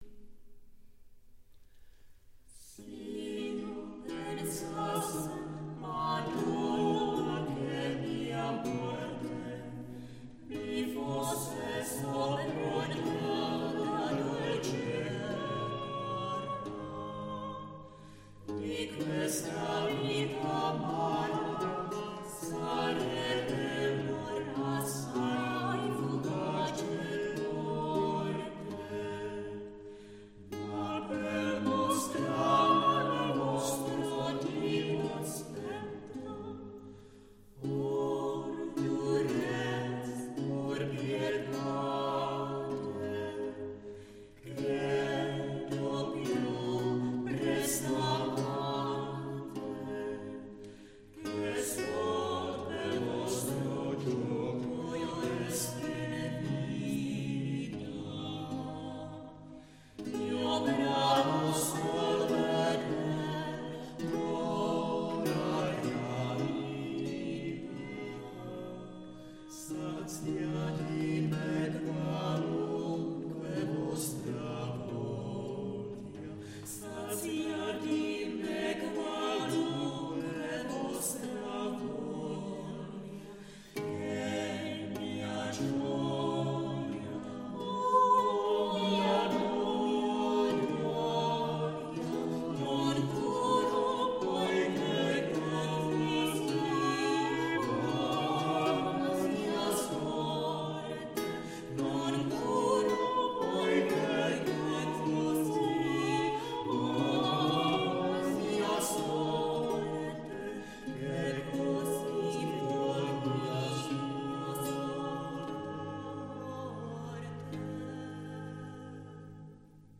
• Italské renesanční madrigaly
Natočeno v Thunské kapli v Děčíně v červnu 2005, loutna